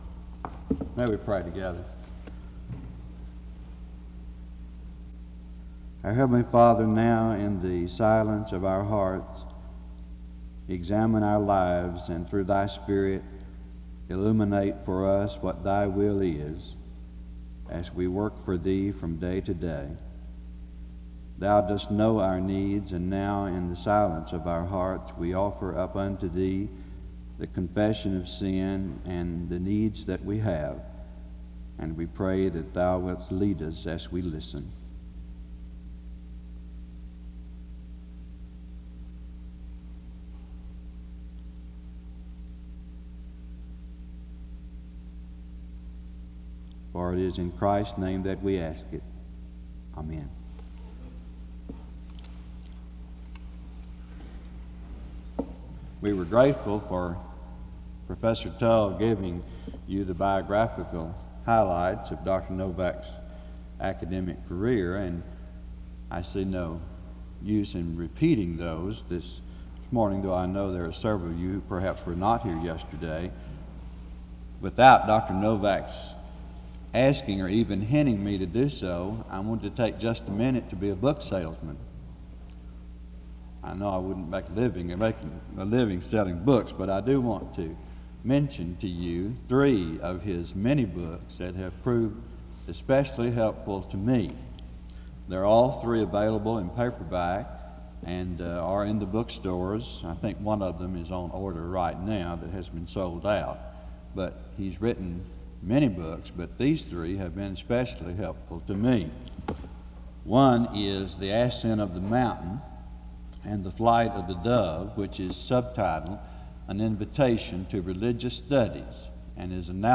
SEBTS_Spring_Lecture_Michael_Novak_1975-04-16.wav